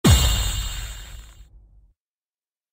Knux Punch Start Sound Effect Download: Instant Soundboard Button